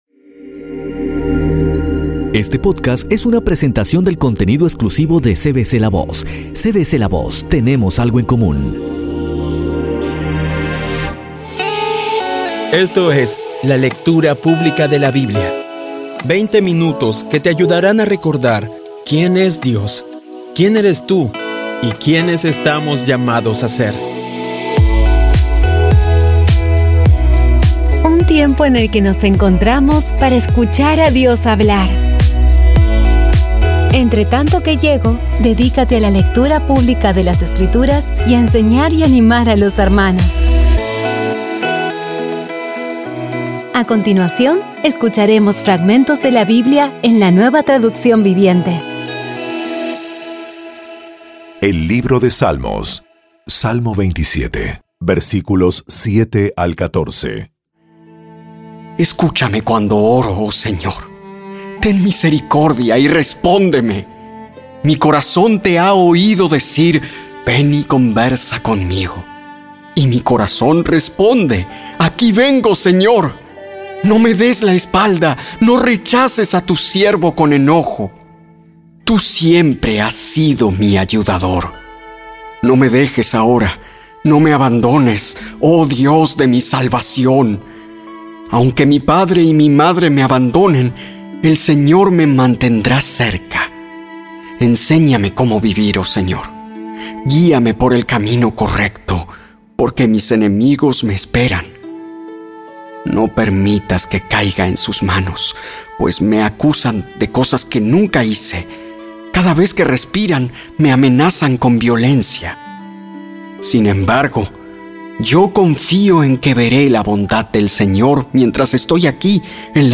Audio Biblia Dramatizada Episodio 53
Poco a poco y con las maravillosas voces actuadas de los protagonistas vas degustando las palabras de esa guía que Dios nos dio.